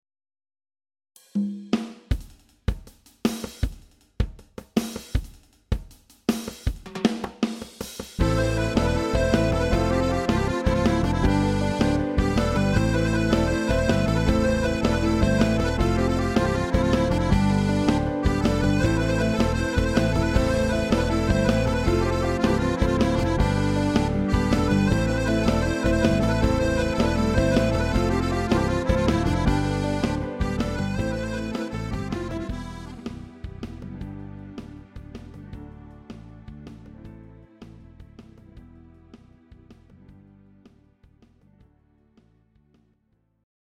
Greek Syrtos Ruba